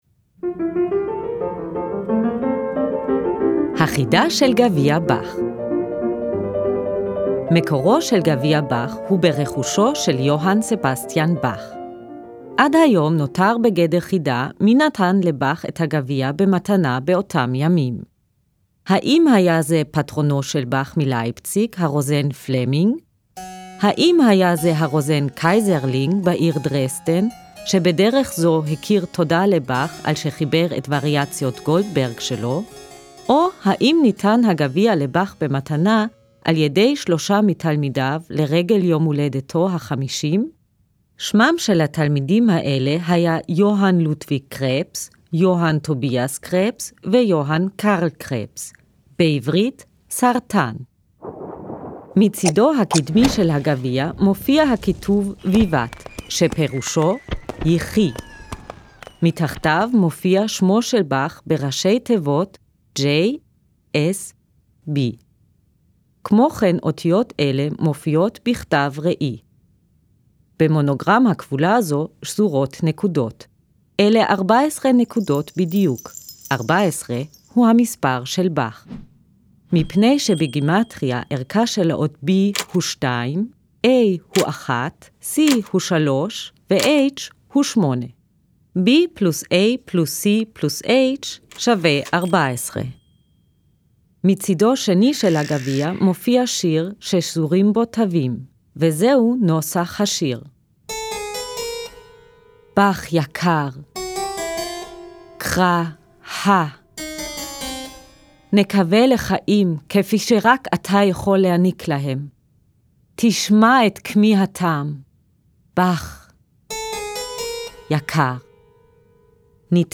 Werbung - Outback Brazil